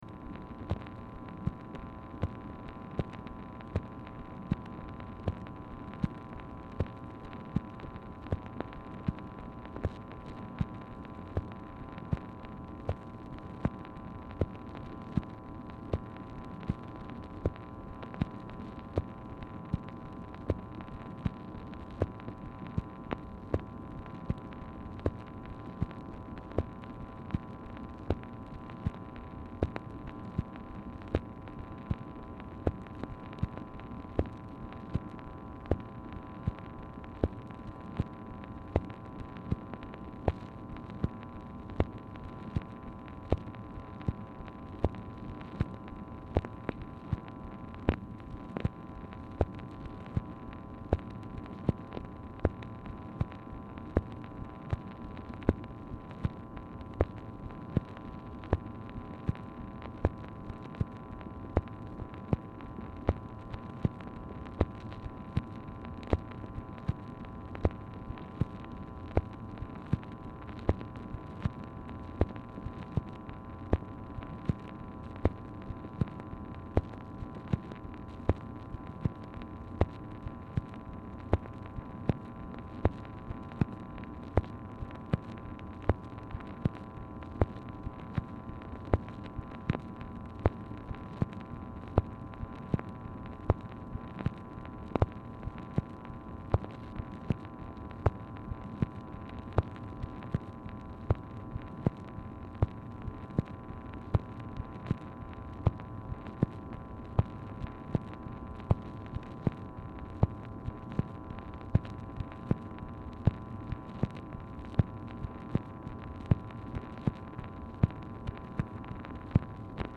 Telephone conversation # 9169, sound recording, MACHINE NOISE, 11/13/1965, time unknown | Discover LBJ
Format Dictation belt
Specific Item Type Telephone conversation